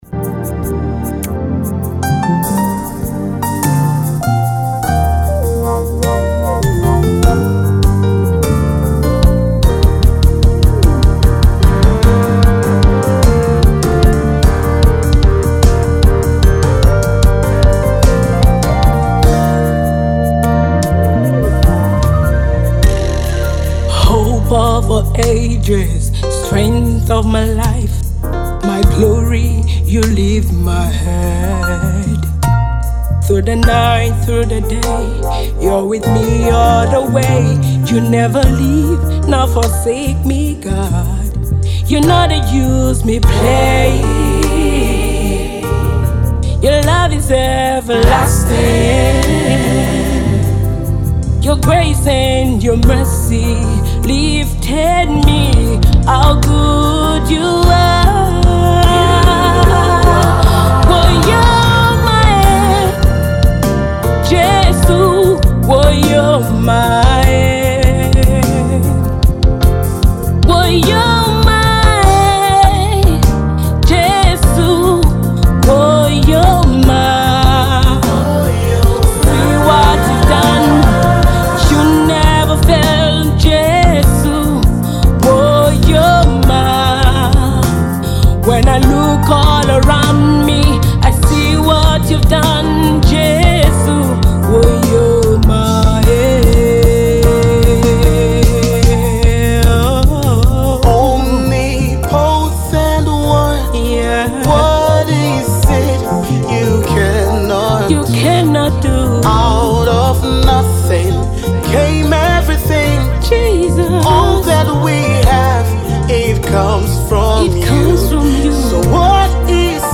The song rendered partly in Urhobo dialect and English
gospel artiste